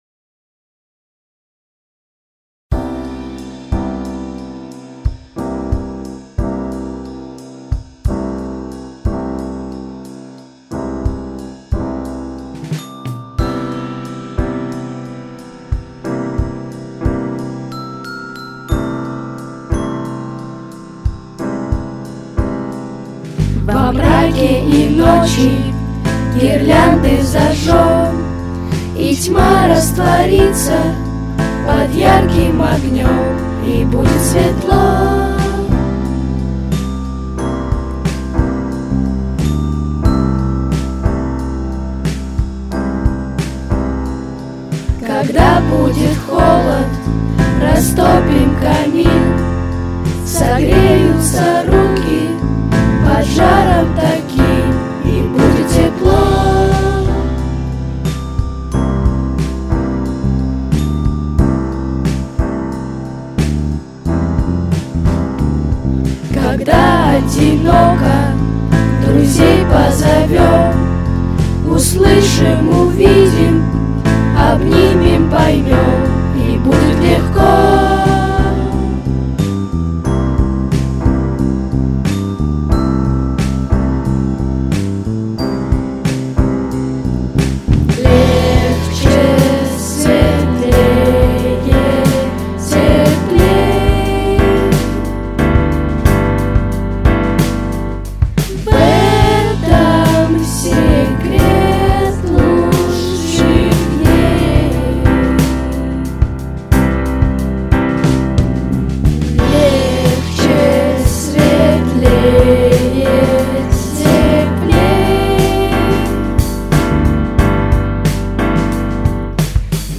XXIV Театральный Фестиваль
Записано в студии Easy Rider в ноябре–декабре 2025 года